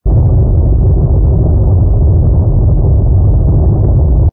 rumble_gunboat.wav